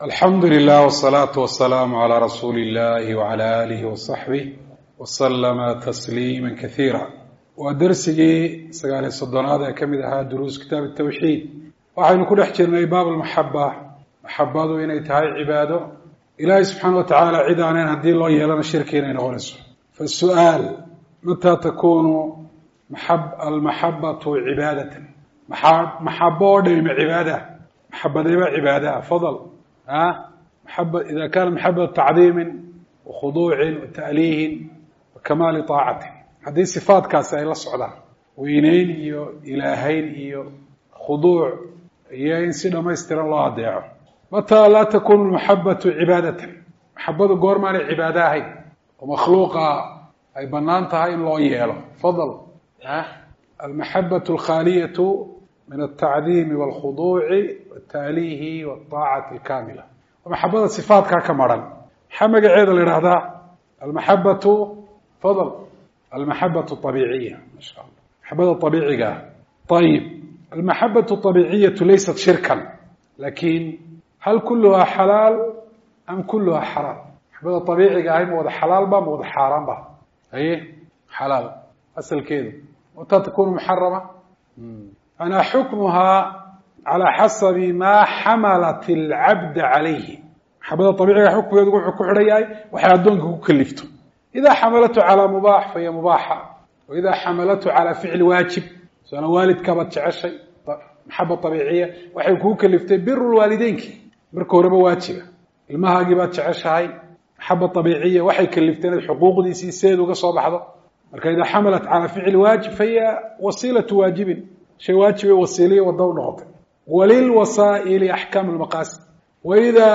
Dawratu Ta-siiliyah Ee Masjidu Rashiid Hargeisa